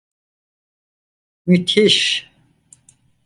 Pronunciado como (IPA)
/myˈtiʃ/